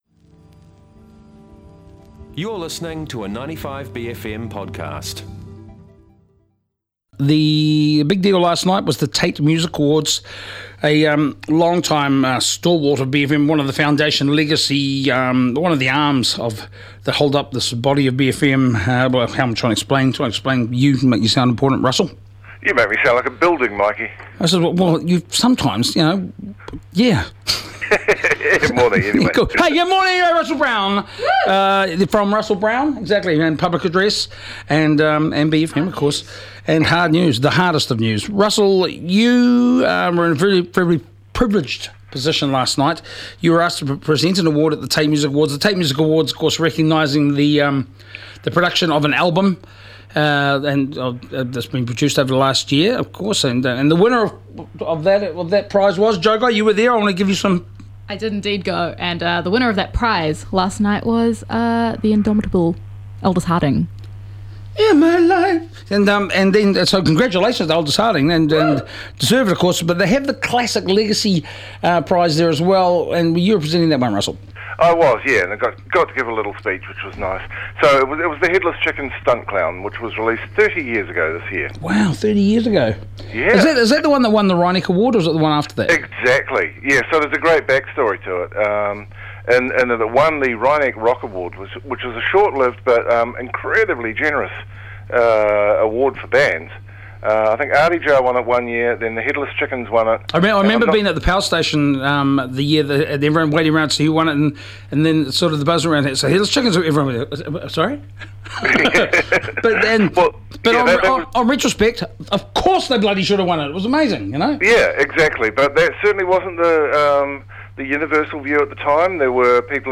But fear not, for bFM alum and resident Tāmaki Makaurau dweller Russell Brown's on the line to chat about all the things that've ruled the headlines (but more importantly, his heart) this week. Today, it's allll about the ~ controversial ~ Taite Music Prize.